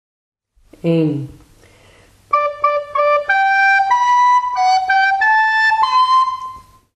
Opa’s notenboom en een team van 4 zingende cachers leidt tot een puzzel, die om noten draait.
Ieder fragmentje is het begin van een, nog steeds bekend Nederlandstalig kinderliedje uit de oude doos.